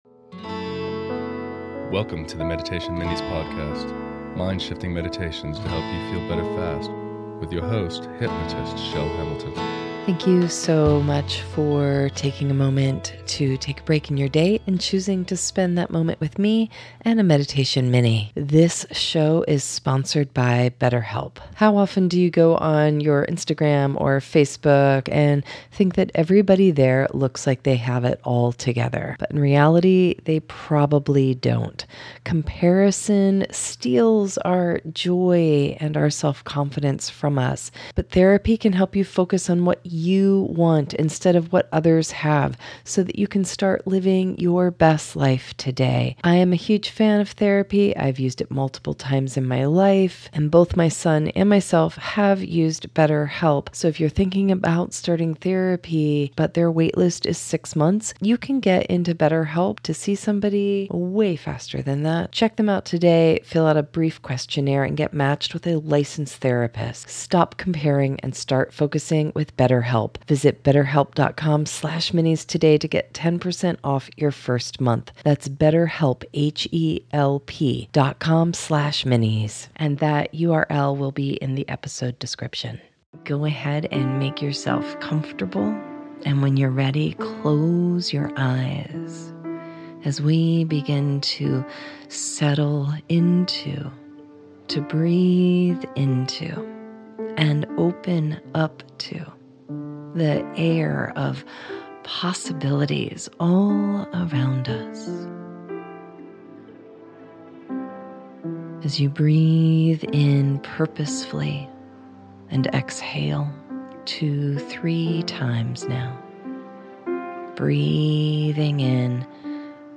Enter into the place of dreaming a new being for you, those you care for, and our world - with this possibility and manifestation focused guided meditation.